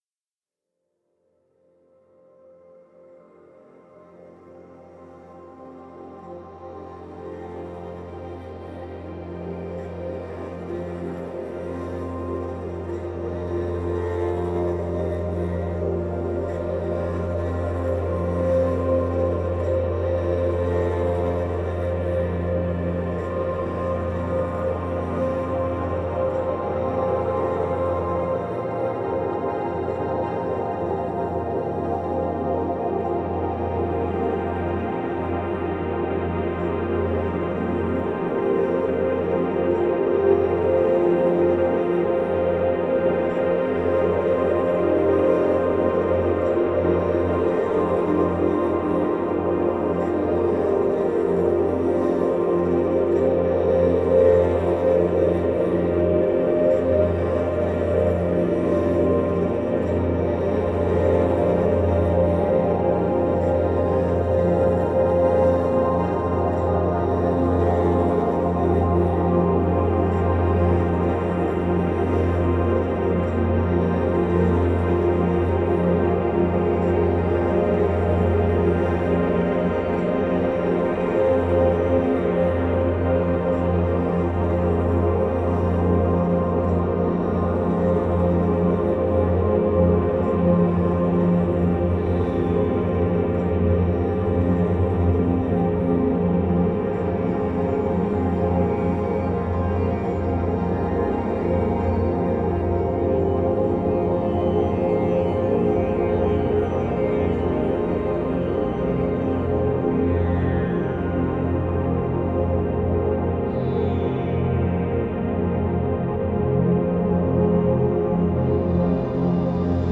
Relaxing music before bed enables you to fall asleep faster and stay asleep longer.